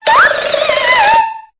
Cri de Brocélôme dans Pokémon Épée et Bouclier.